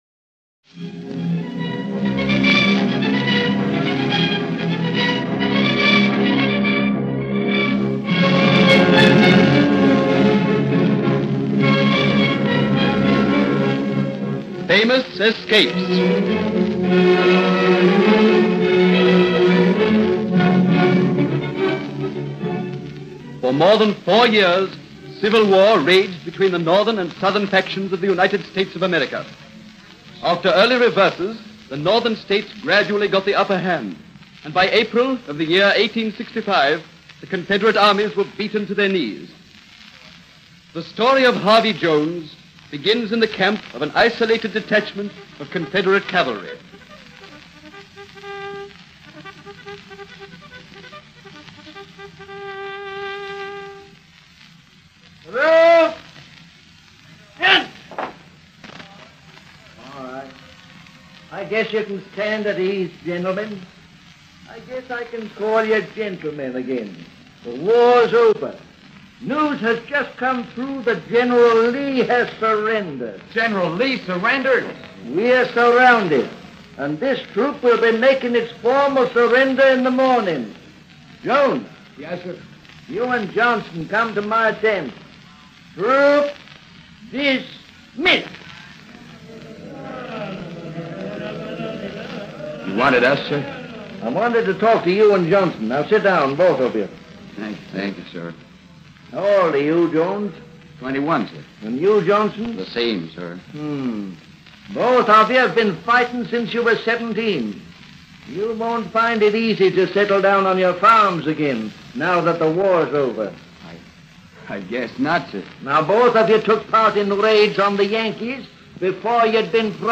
Famous Escapes was a captivating radio series produced in Australia around 1945.